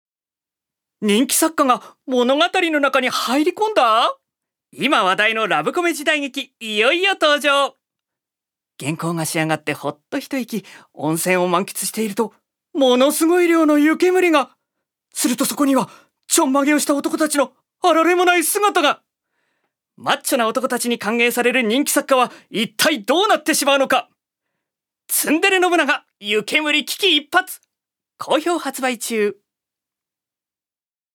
所属：男性タレント
ナレーション３